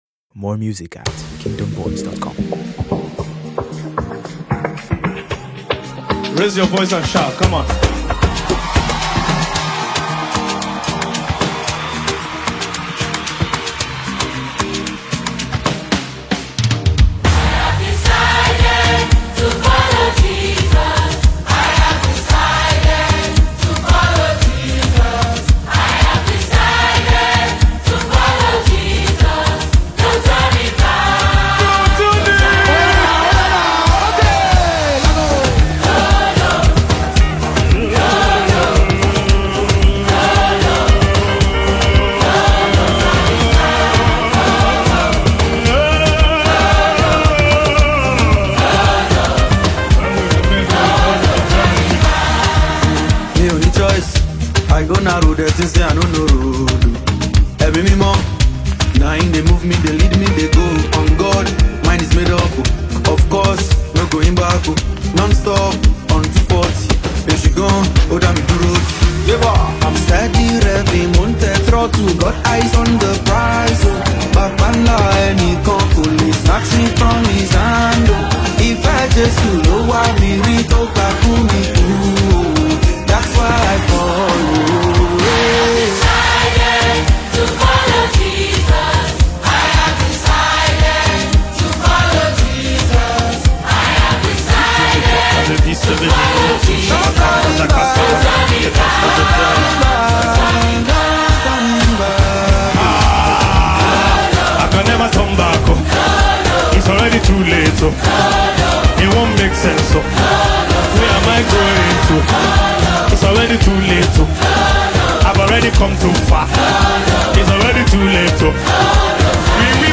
soaring vocals
If you love gospel music that moves both heart and feet